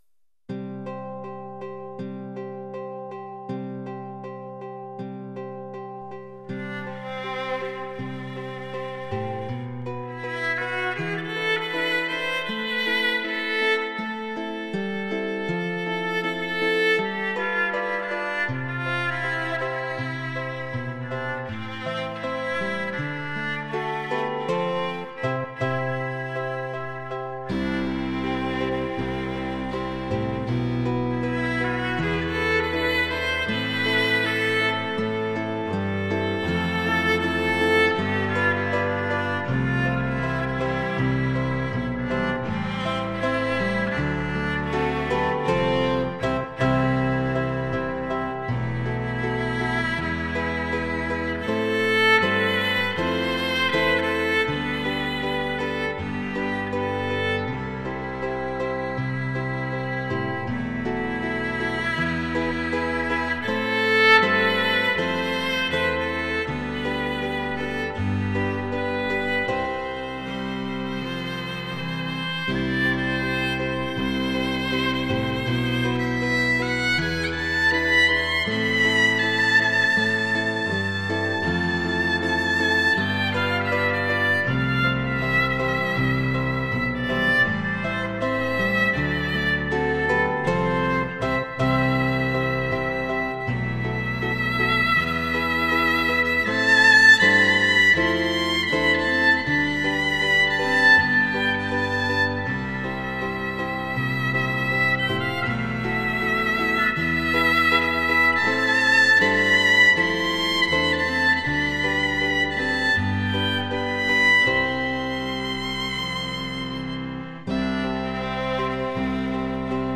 housle